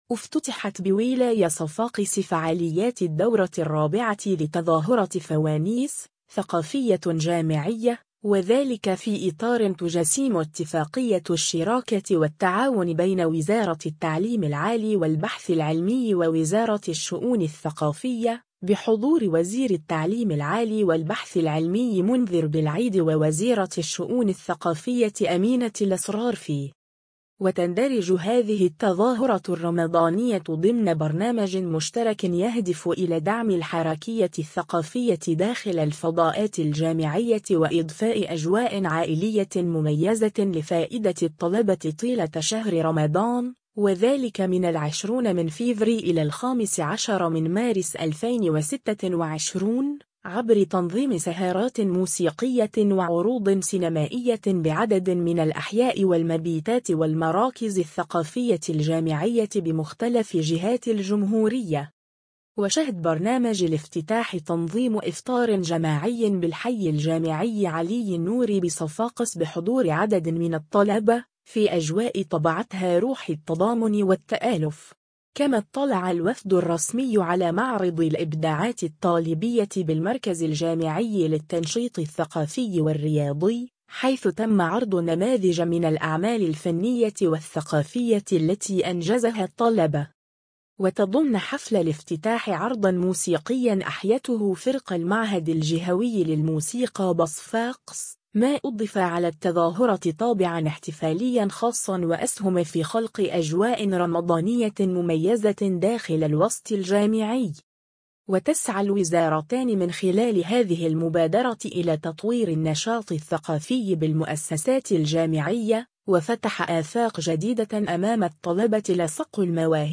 و تضمّن حفل الافتتاح عرضاً موسيقياً أحيته فرقة المعهد الجهوي للموسيقى بصفاقس، ما أضفى على التظاهرة طابعاً احتفالياً خاصاً وأسهم في خلق أجواء رمضانية مميزة داخل الوسط الجامعي.